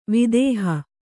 ♪ vidēha